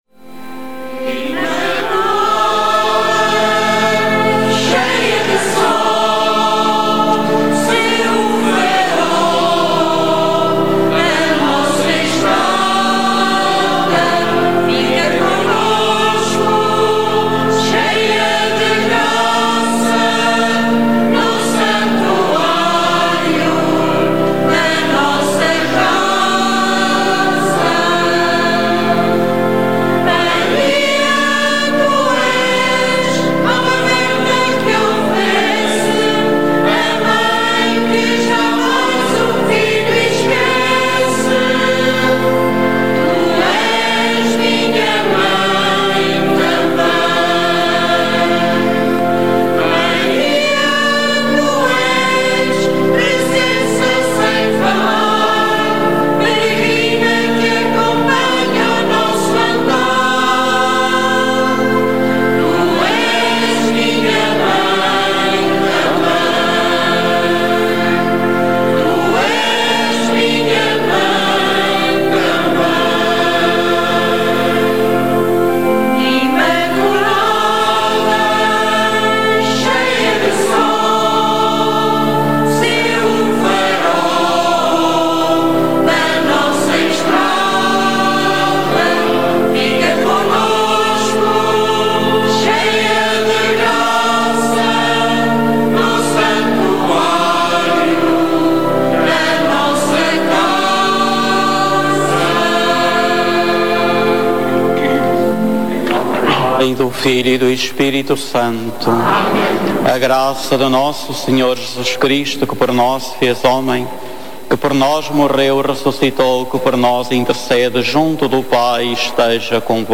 A Rádio Clube de Lamego transmitiu no dia 8 de Dezembro a eucaristia em honra da Imaculada Conceição.